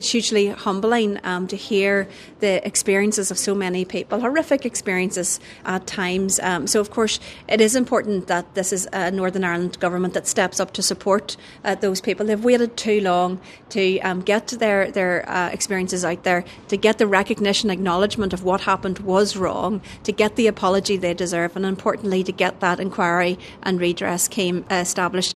Deputy First Minister Emma-Little Pengelly says they deserve an apology and compensation…………